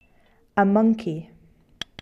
animal1 monkey
animal1-monkey.mp3